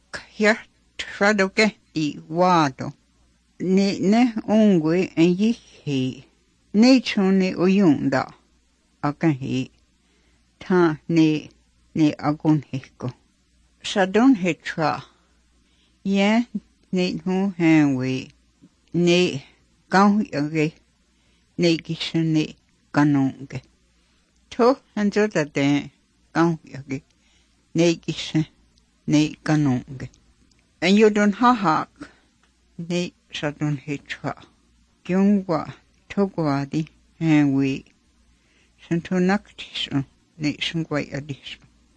9 March 2014 at 9:43 pm There are a lot of tones, but the tonal pattern and phonology doesn’t sound east Asian.